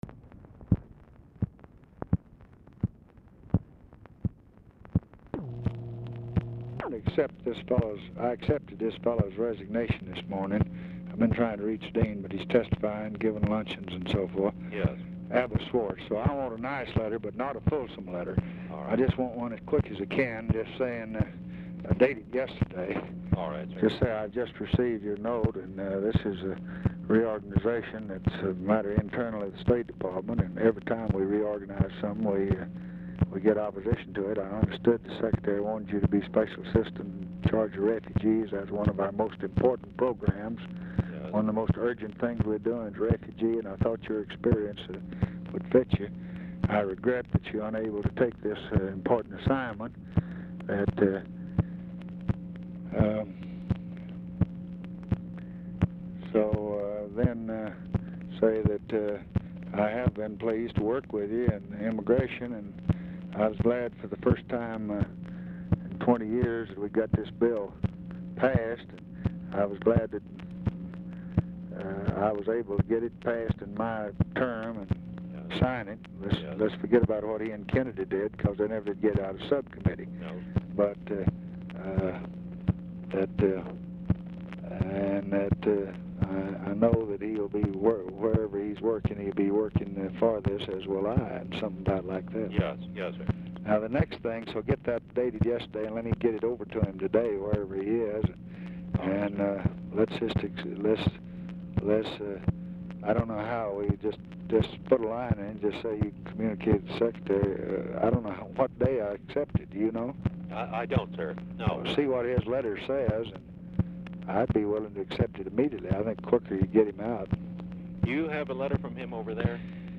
Telephone conversation # 9849, sound recording, LBJ and U. ALEXIS JOHNSON, 3/7/1966, 2:55PM | Discover LBJ
RECORDING STARTS AFTER CONVERSATION HAS BEGUN
Dictation belt